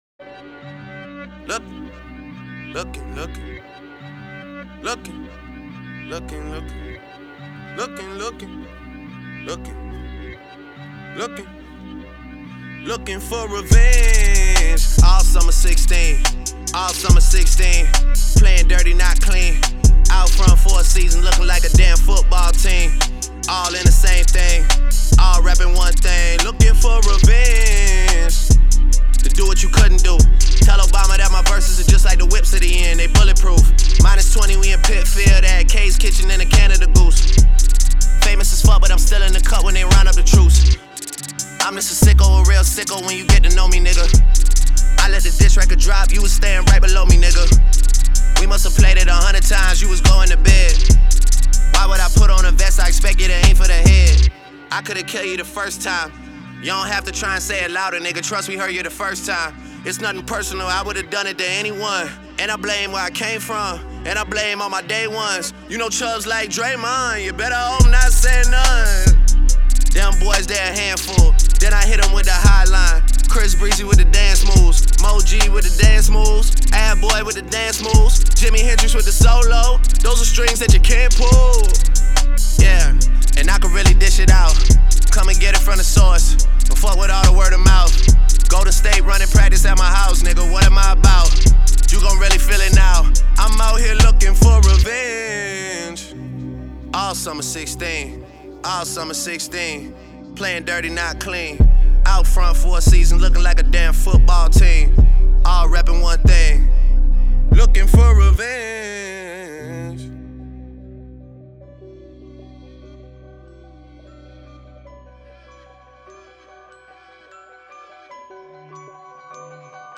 as both emcees have dropped new diss records.